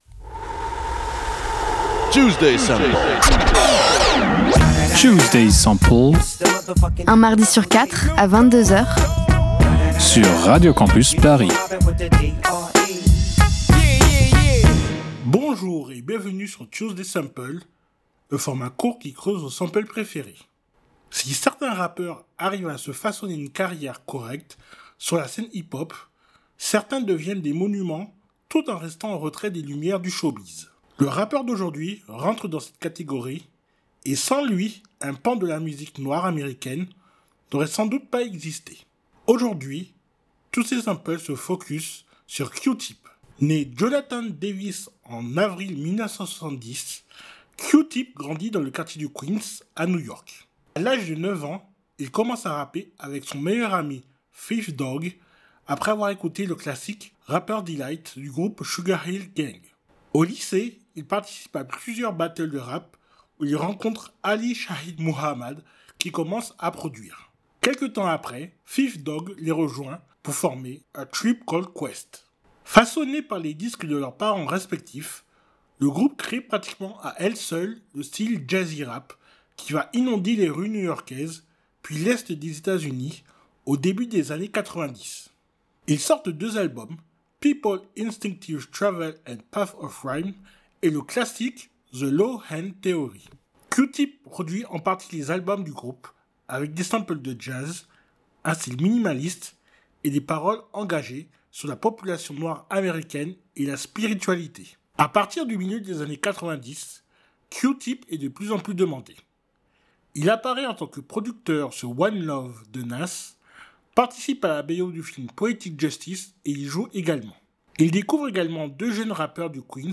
Type Musicale Hip-hop